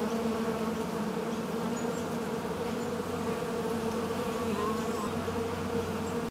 swarm.ogg